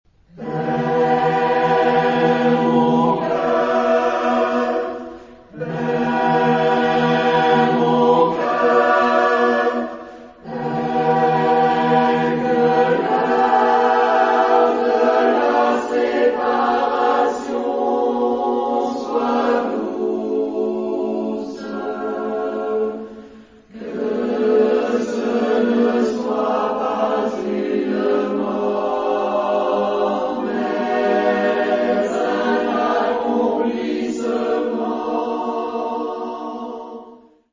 Genre-Style-Form: Secular ; Poem
Mood of the piece: moving ; gentle
Type of Choir: SATB  (4 mixed voices )
Tonality: E minor